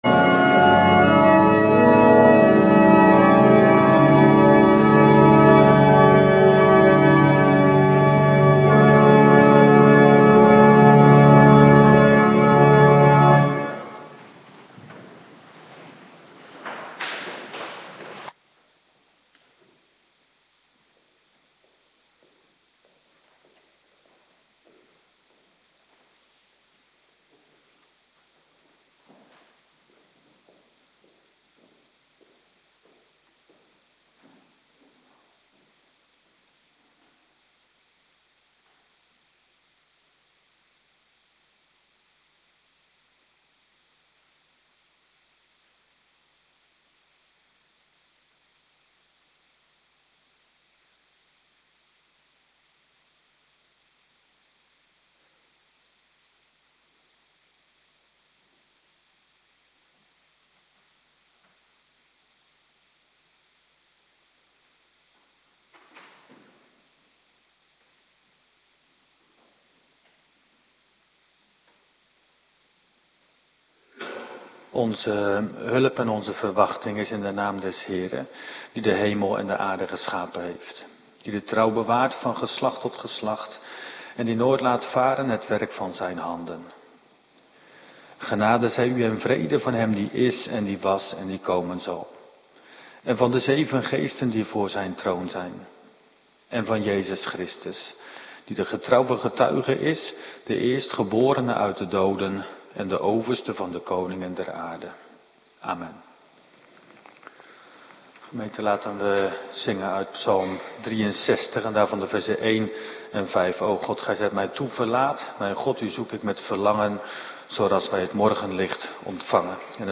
Woensdagavond Bijbellezing